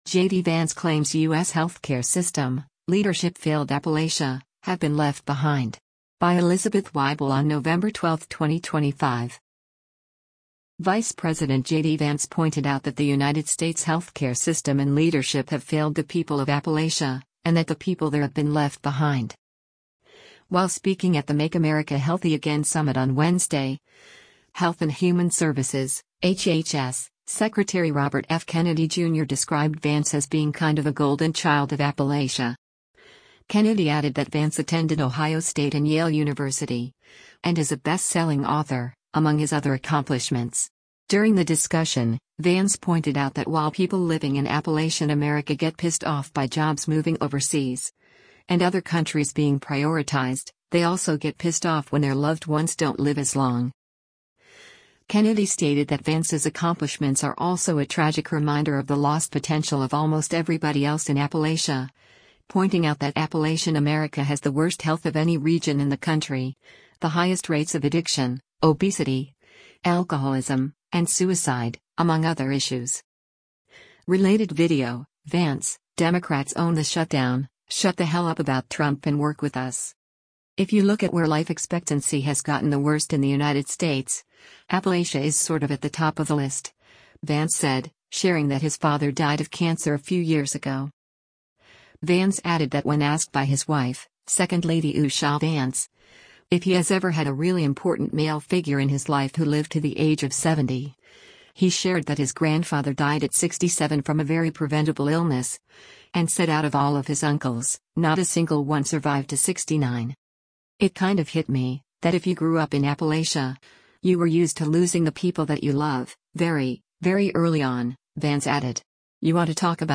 While speaking at the Make America Healthy Again Summit on Wednesday, Health and Human Services (HHS) Secretary Robert F. Kennedy Jr. described Vance as being “kind of a golden child of Appalachia.”